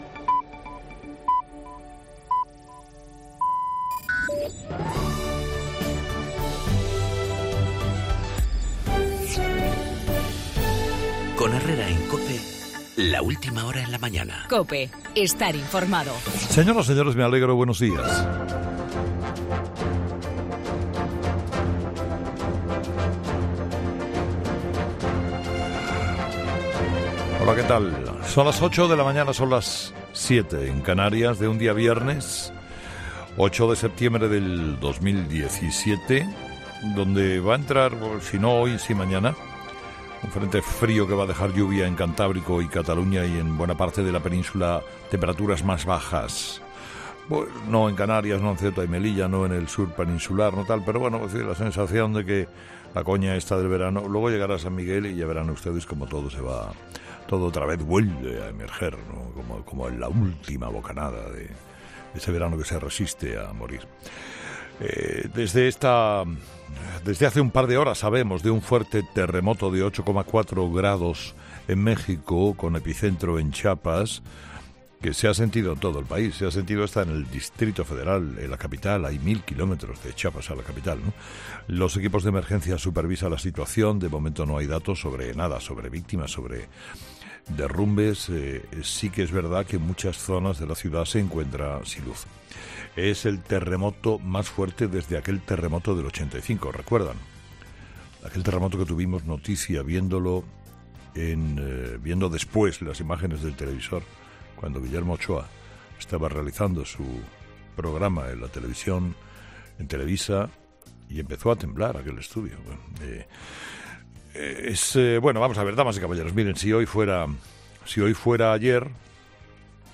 AUDIO: La nueva jornada del desafío independentista catalán, en el monólogo de Carlos Herrera a las 8 de la mañana.